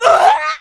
death3c.ogg